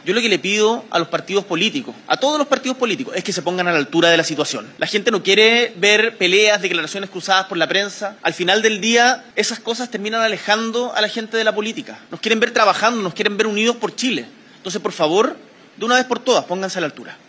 Desde el Palacio de La Moneda, y tras entregar una declaración de prensa para anunciar nuevas ayudas tempranas a las familias damnificadas, el Mandatario se refirió a la tensión generada por la reunión que sostendrán este viernes el Socialismo Democrático y la Democracia Cristiana, sin la participación del Frente Amplio ni del Partido Comunista, con miras a proyectar su rol como oposición en el próximo gobierno.